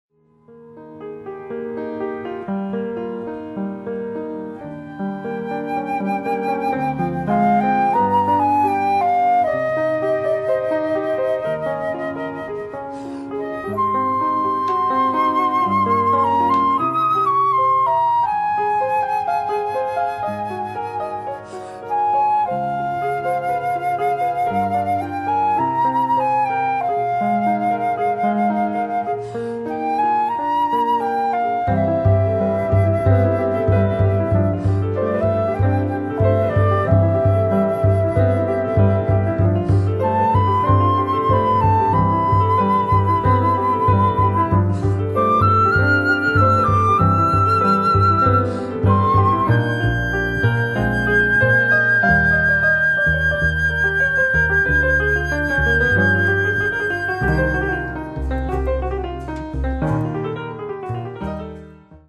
Jazz and More
flute and bass flute
drums